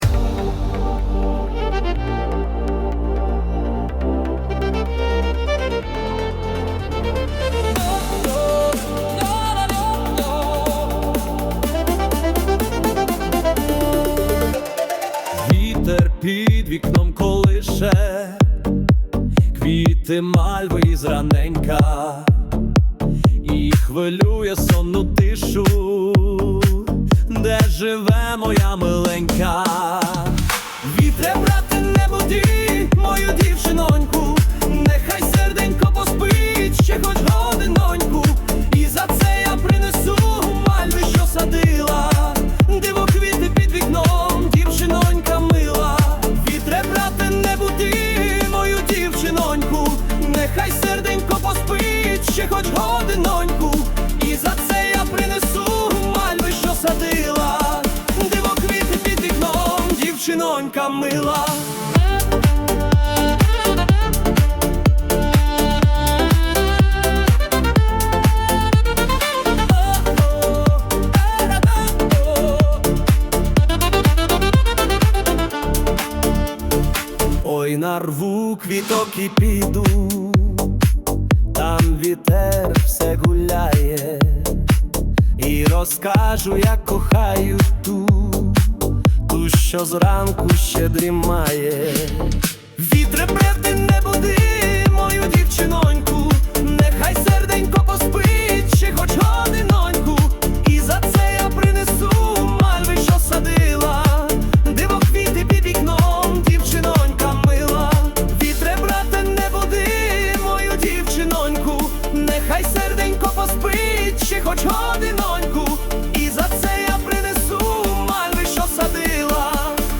Українські Ремікси 2025